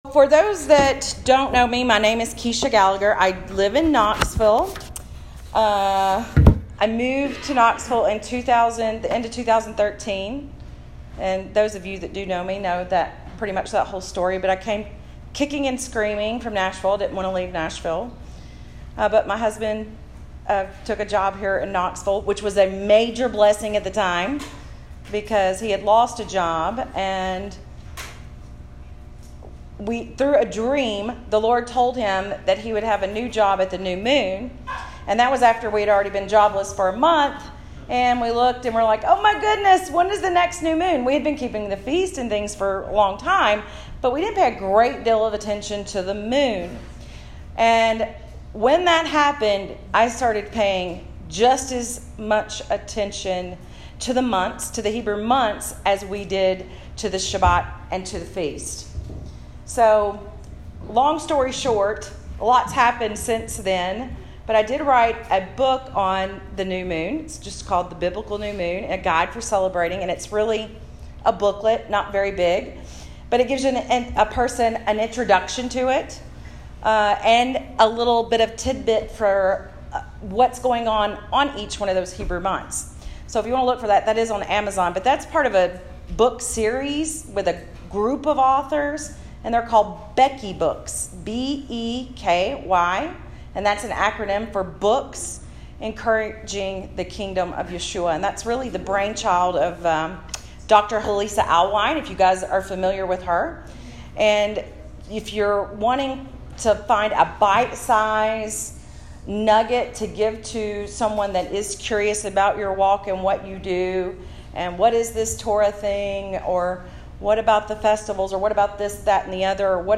One of the names for the Feast of Sukkot is the Clouds of Glory. Learn why in this message I delivered at Sukkot.